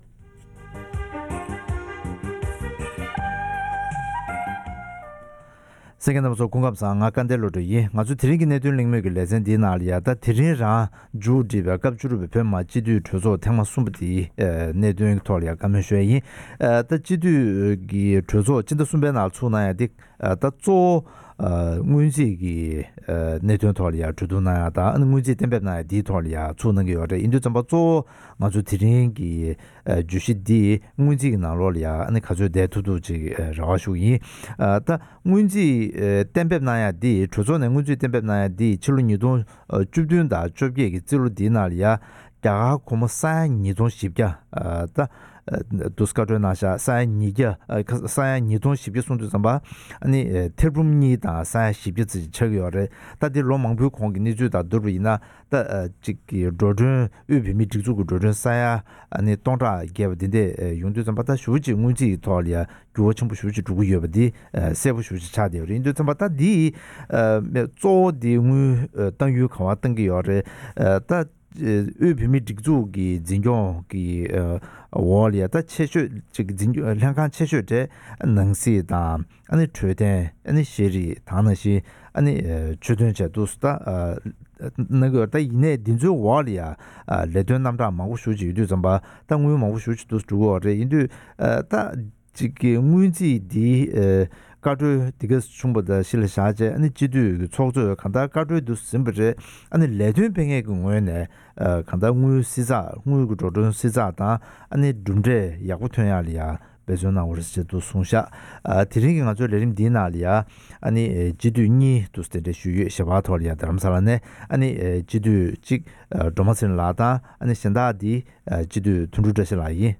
ཐེངས་འདིའི་གནད་དོན་གླེང་མོལ